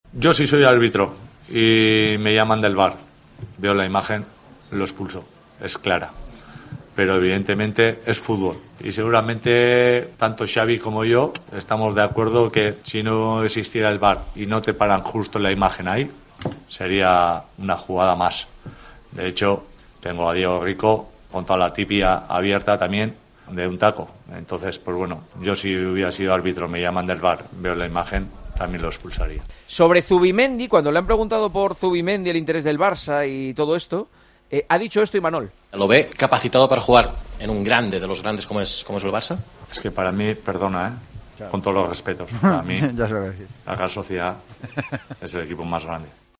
AUDIO: El entrenador de la Real Sociedad opinó en la rueda de prensa posterior al partido contra el Barcelona sobre la expulsión de su jugador en el minuto 40.